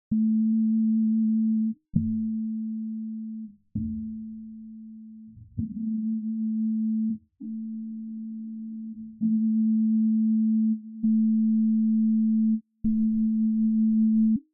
Analyzer : Remove overtones by EQ
The tones above are the instruments that you just heard at the beginning of this article.
All the sounds became tasteless.
P-timbres-fundamental.mp3